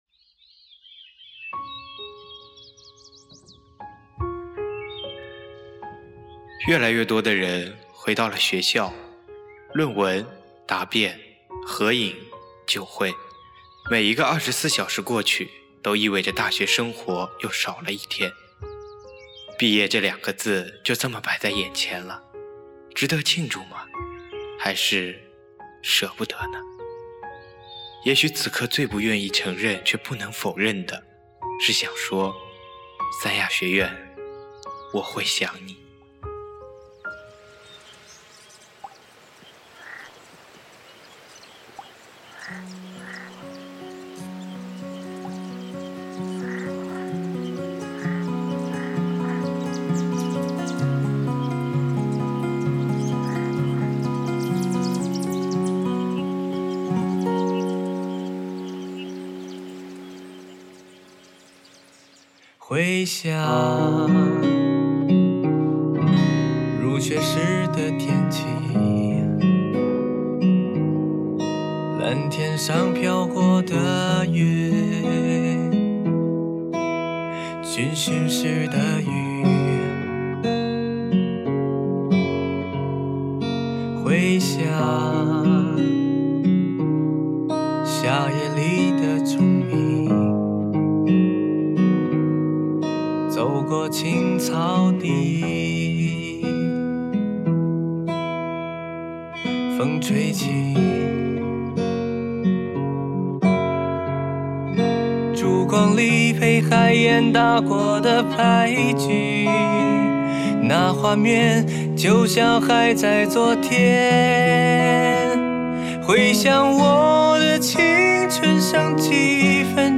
会想吉他慢速mp3111.mp3
吉他
・ 念白部分背景音乐来源网络，对原作者表示感谢